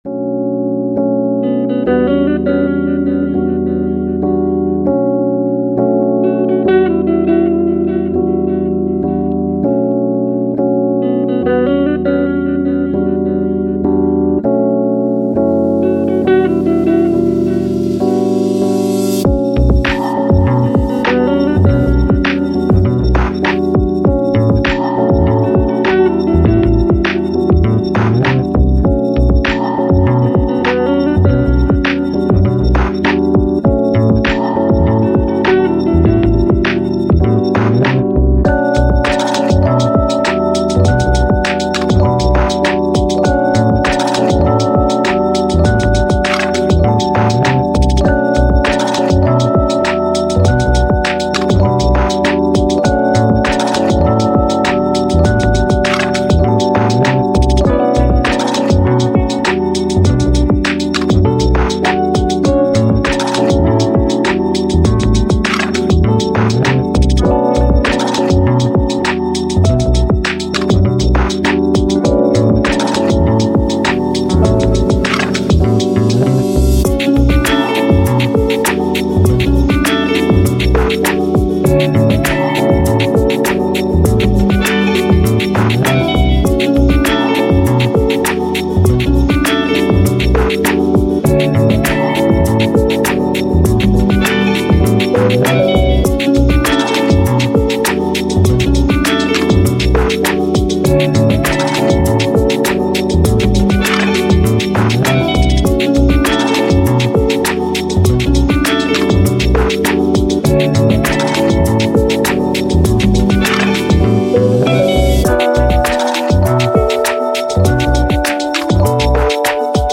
Ambient, IDM, Electronic, Upbeat